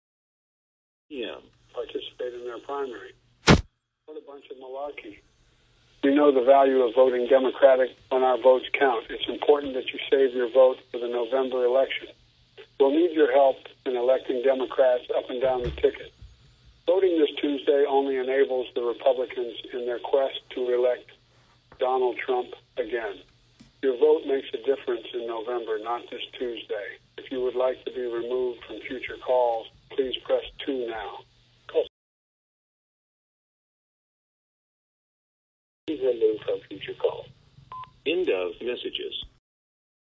• A voice claiming to be “President Biden” called people to say the New Hampshire primary was “a bunch of malarkey”.
A fake phone call
ai-generate-Biden.mp3